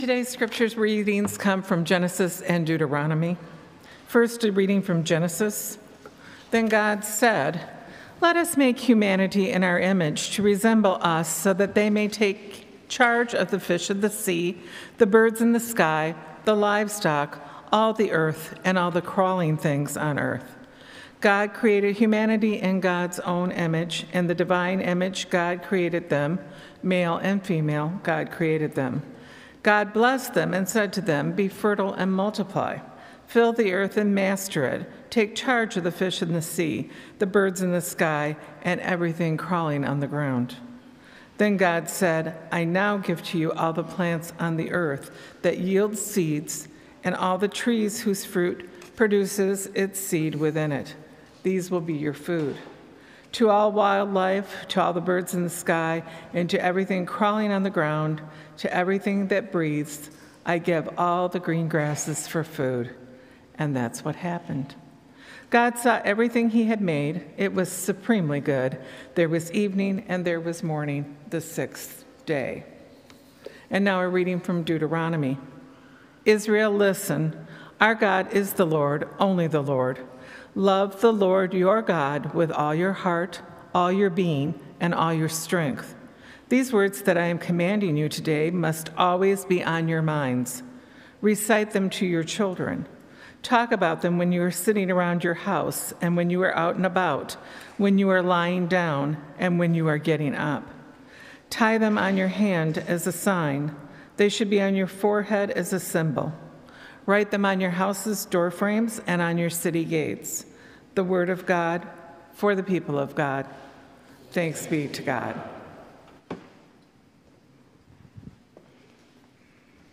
Communion will be served and we’ll celebrate a kindergarten milestone and bless backpacks for all!